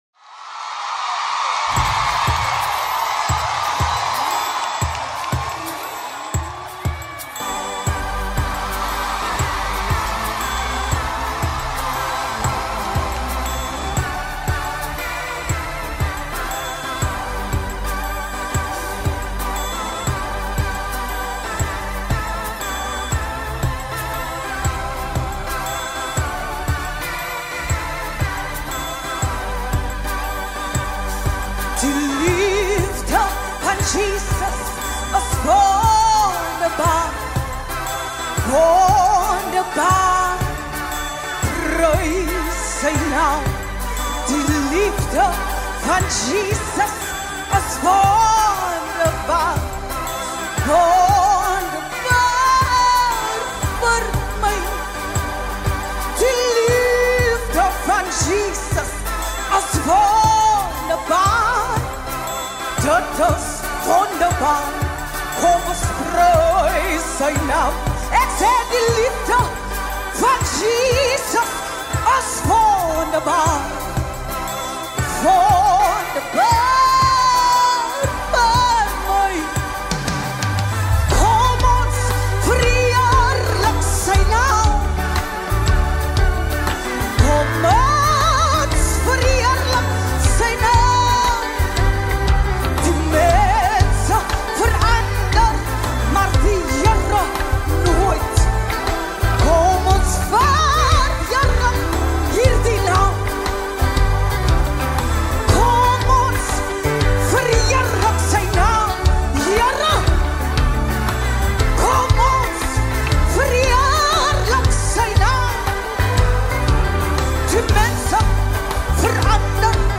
LATEST 2024 LIVE SOUTH AFRICAN WORSHIP SONG